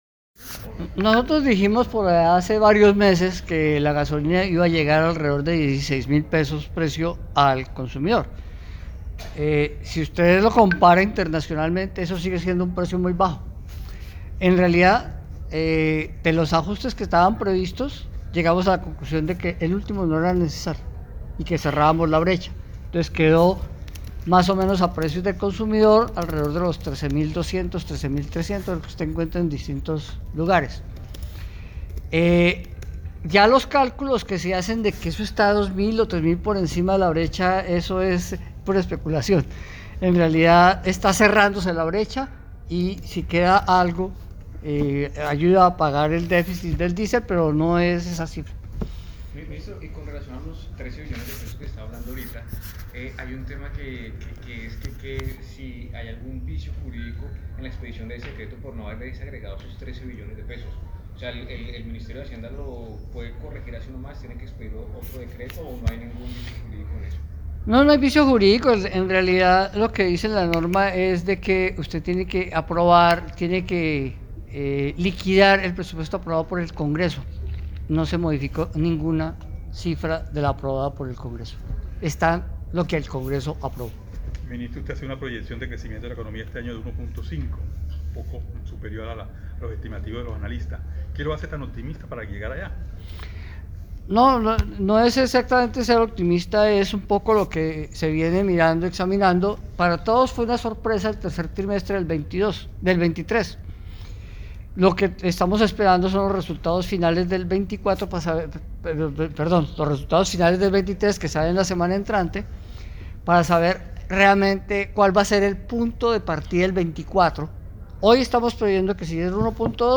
Descargar Reuda de Prensa Ministro de Hacienda